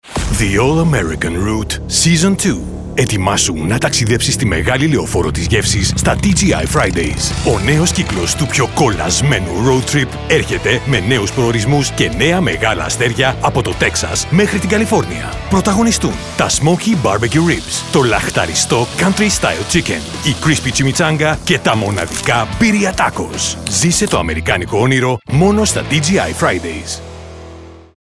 Male
Adult (30-50)
Animation, Gaming, Audio Books, Audio Description, Authoritative, Charming, Commercial, Compelling, Engaging, Confident, Continuity, Cool, Contemporary, Corporate, Documentary, Narrative, Deep, e-learning, Educational, Energetic, Vibrant, Upbeat, Fresh, Fun, Inspirational, International Dubbing, Light Entertainment, Movie Trailer, Positive, Bright,
All our voice actors have professional broadcast quality recording studios.
Male Voice Over Talent